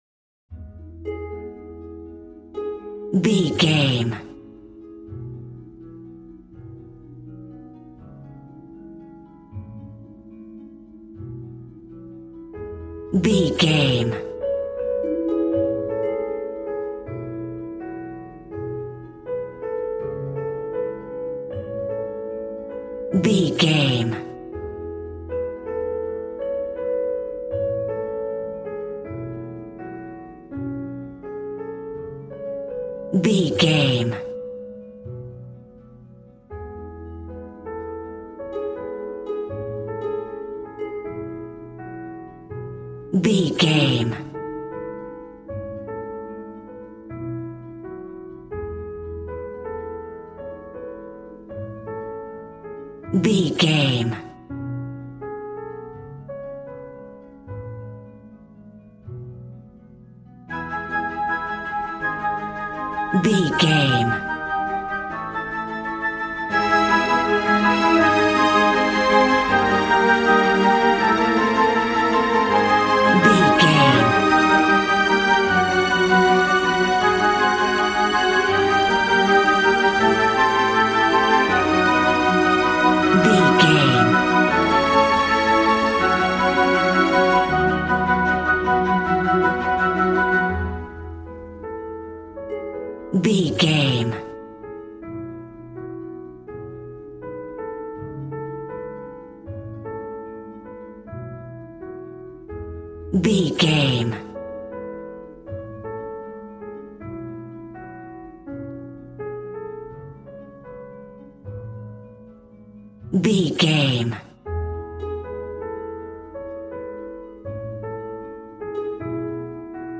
Regal and romantic, a classy piece of classical music.
Aeolian/Minor
A♭
regal
strings
violin